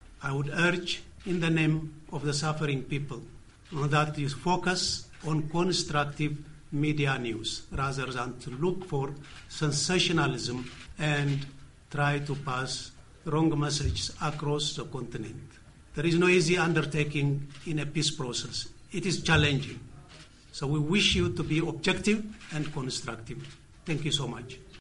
Clip 5 IGAD Envoy, Amb. Seyoum Mesfin, at South Sudan addresses news media at press conference